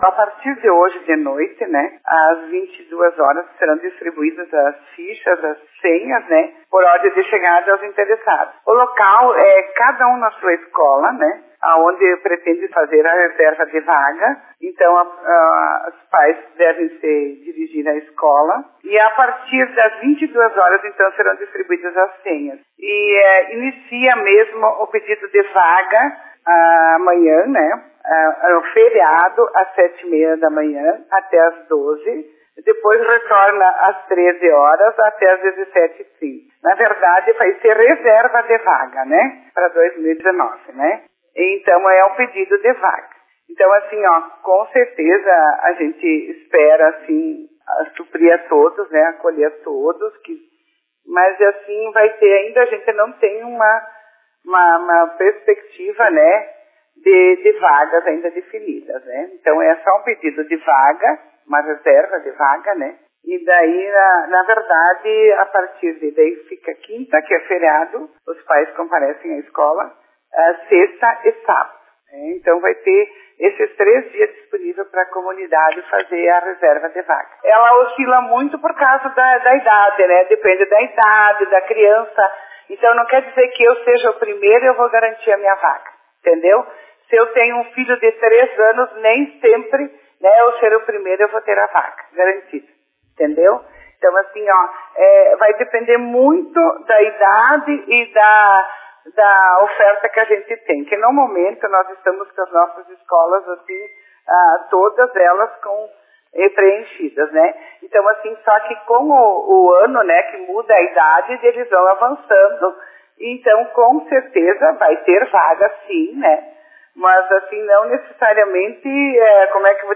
Em entrevista à Rádio Comunitária, a Secretária de Educação, Carmen Giovenardi, falou sobre o período de matrículas e orientou os pais que buscam vagas nas creches frederiquenses: